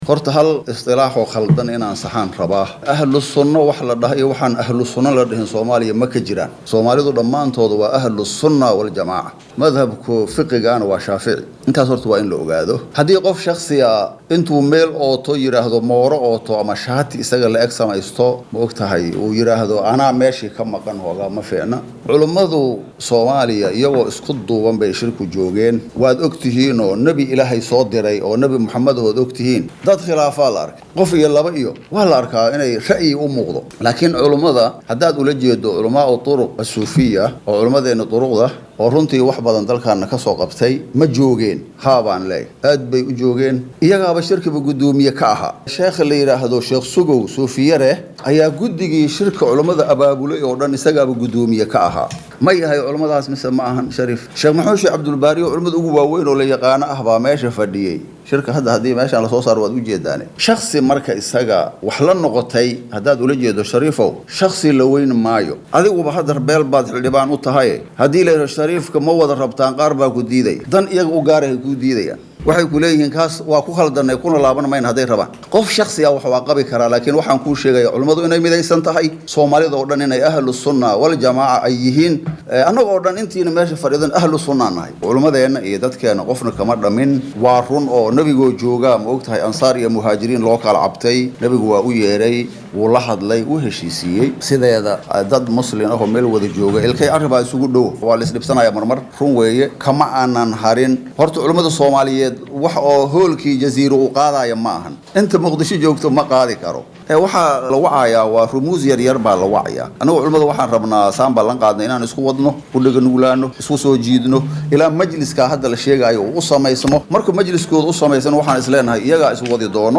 Wasiirka wasaaradda awqaafta iyo arrimaha diinta ee xukumadda Soomaaliya Sheekh Mukhtaar Roobow ayaa hortagay guddiga arrimaha diinta ee golaha shacabka oo su’aalo adag ka weydiiyey arrimo ay ku lug leedahay wasaaradda uu hoggaamiyo.